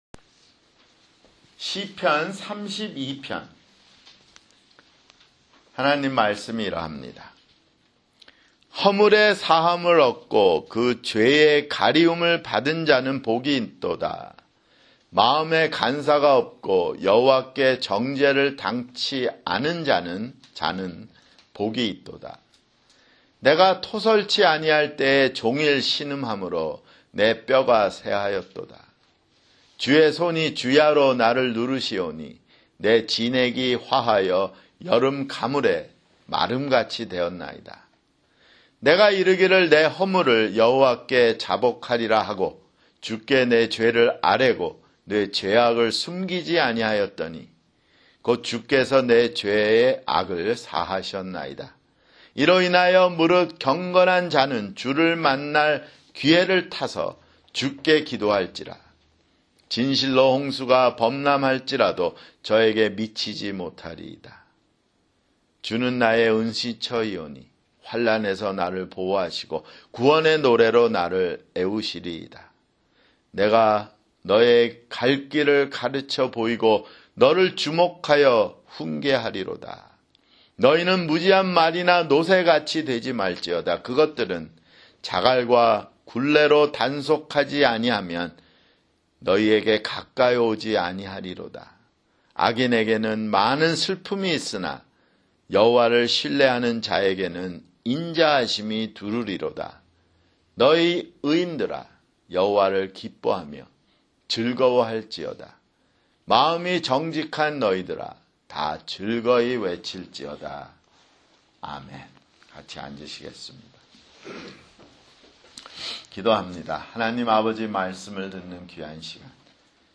[주일설교] 시편 (24)